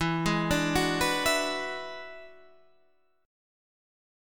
E9 chord {x 7 6 7 7 7} chord